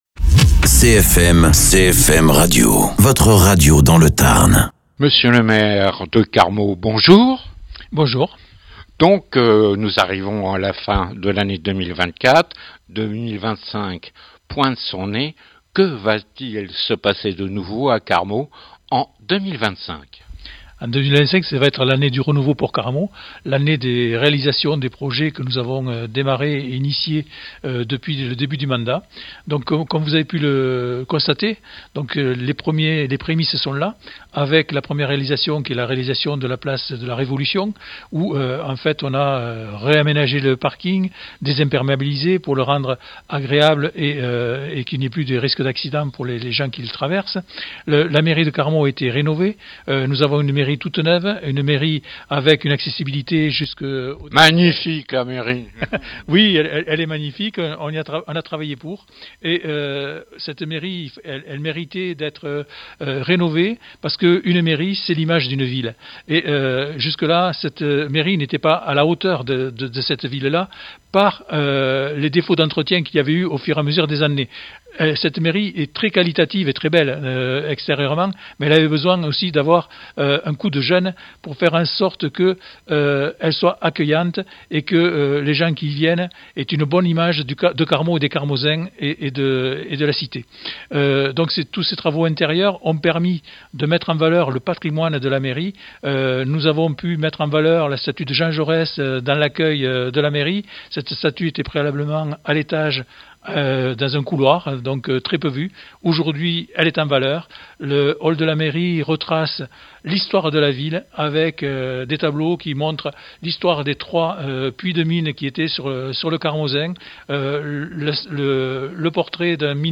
Interviews
Invité(s) : Jean-Louis Bousquet, maire de Carmaux.